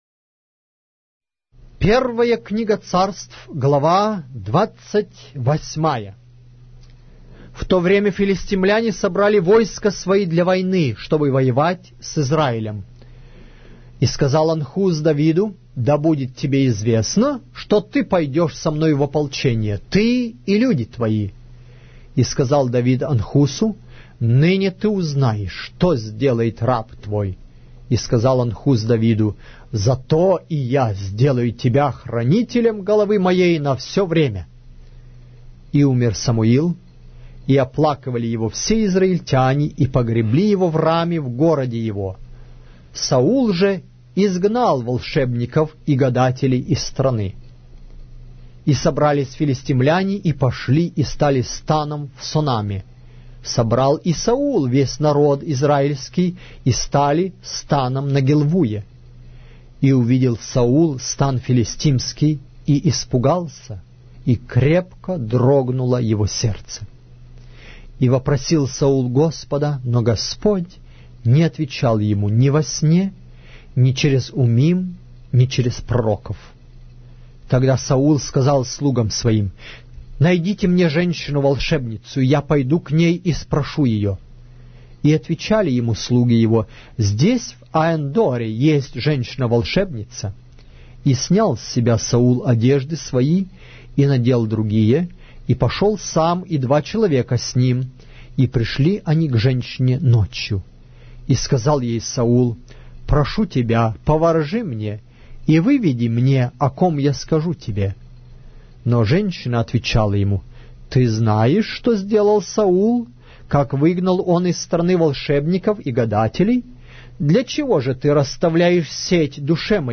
Глава русской Библии с аудио повествования - 1 Samuel, chapter 28 of the Holy Bible in Russian language